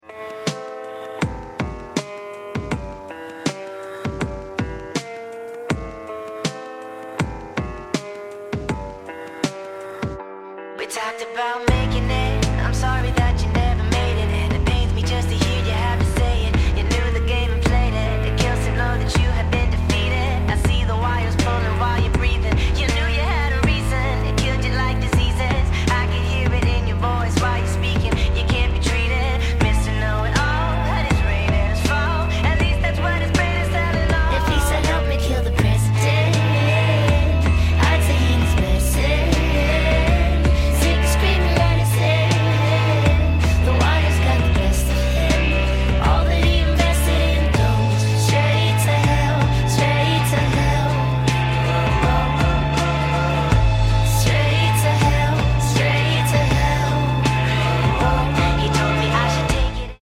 sped up